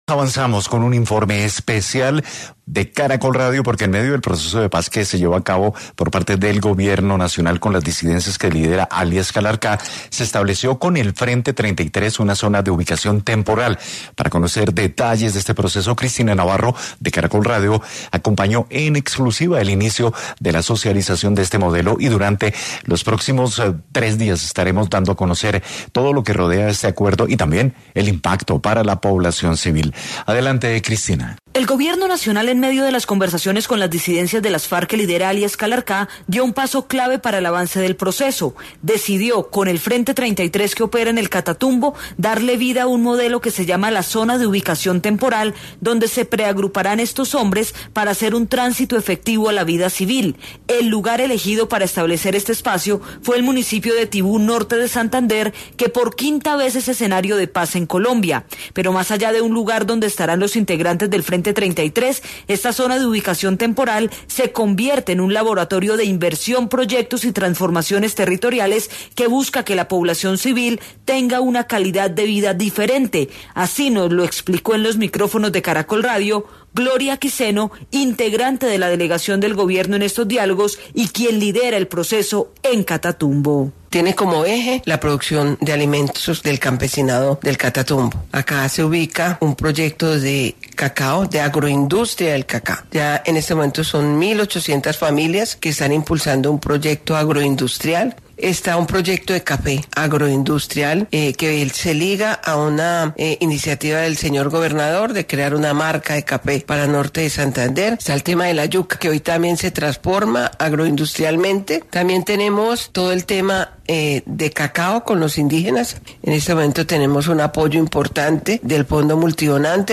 Así nos lo explicó en exclusiva en los micrófonos de Caracol Radio, Gloria Quiceno, integrante de la delegación del Gobierno en estos diálogos y quien lidera el proceso en Catatumbo.